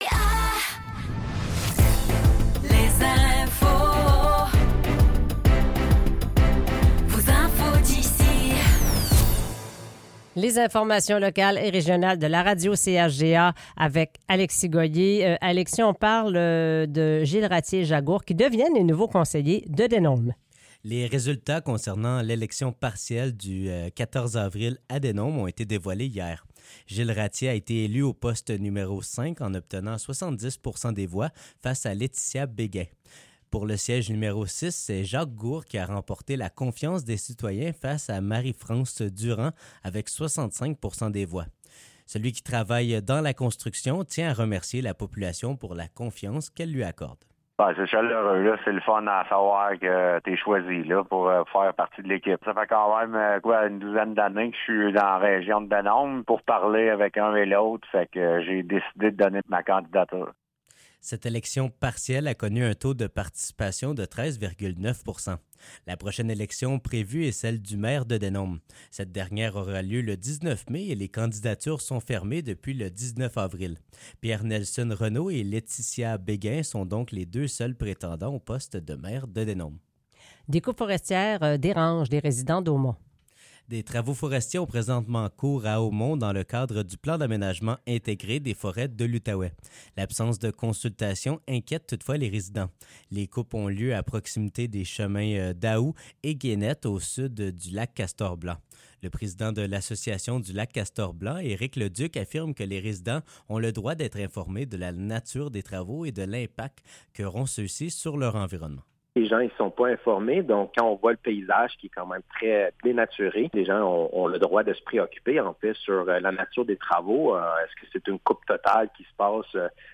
Nouvelles locales - 23 avril 2024 - 7 h